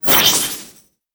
sfx_skill 09_1.wav